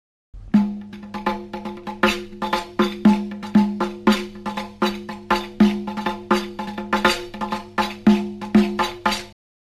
The Samaii is distinguished by having a rhythm of ten fast beats
05 2Samaii 10 Rhythm.mp3